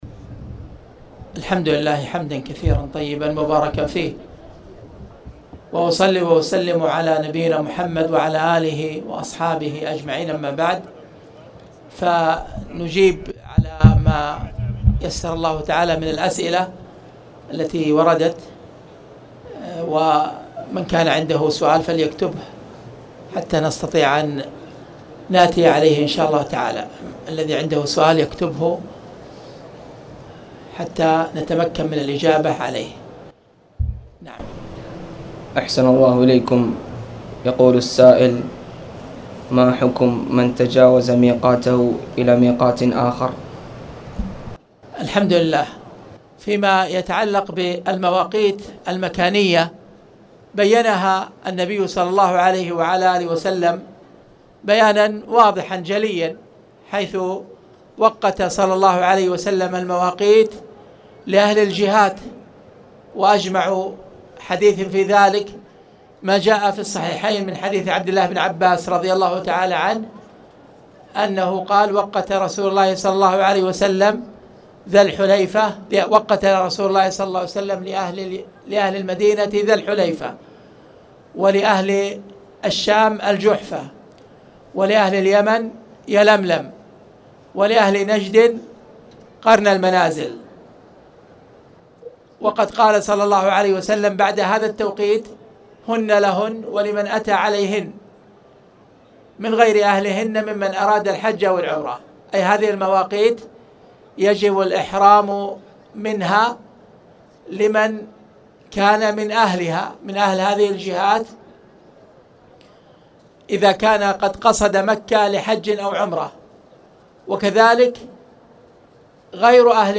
تاريخ النشر ٢٨ ربيع الثاني ١٤٣٨ هـ المكان: المسجد الحرام الشيخ: فضيلة الشيخ أ.د. خالد بن عبدالله المصلح فضيلة الشيخ أ.د. خالد بن عبدالله المصلح كتاب الطهارة _باب المياه (2)